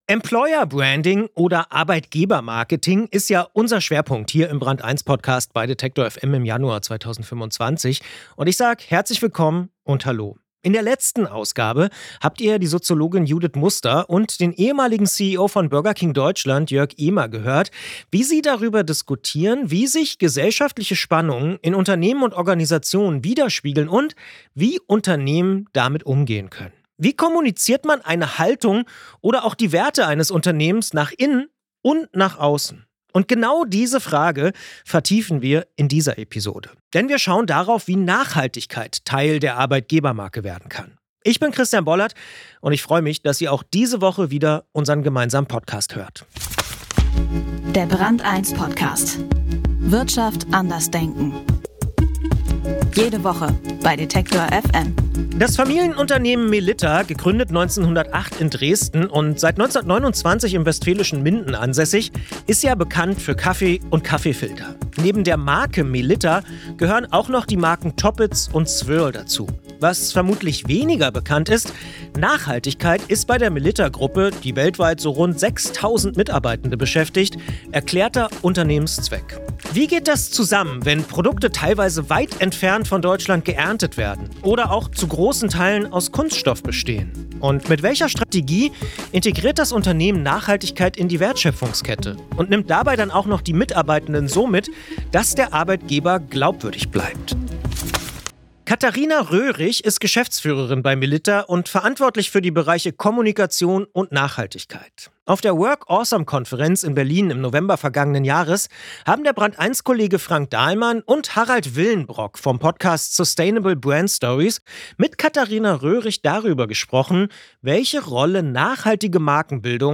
Auf der Work Awesome Konferenz in Berlin im November 2024